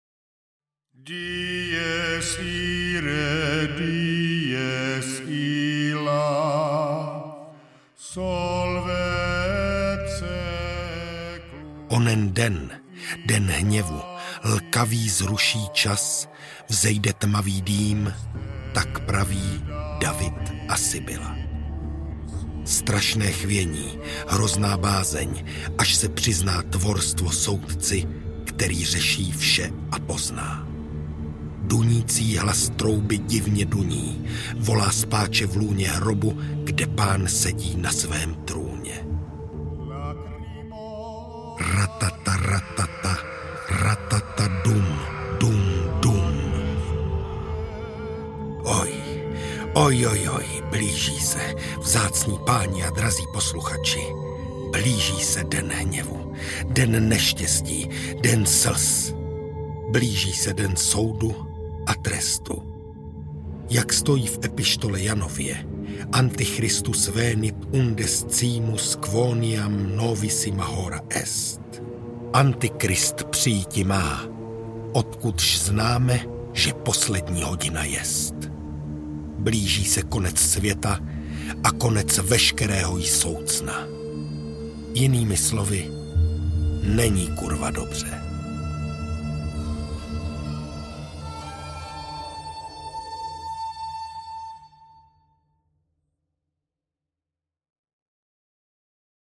Lux Perpetua audiokniha
Ukázka z knihy